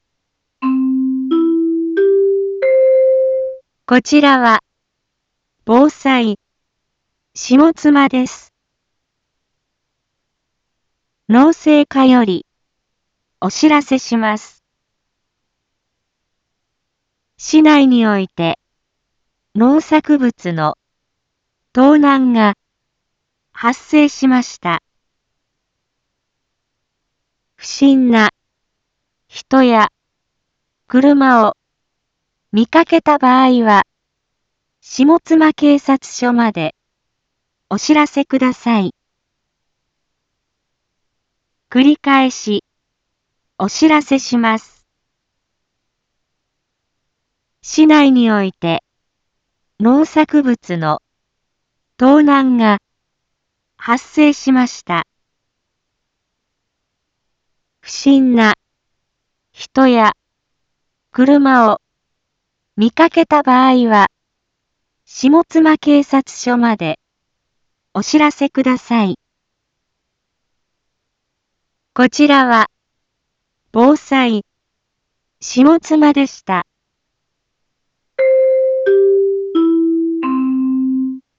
Back Home 一般放送情報 音声放送 再生 一般放送情報 登録日時：2022-09-01 12:31:25 タイトル：農作物の盗難被害について インフォメーション：こちらは、防災、下妻です。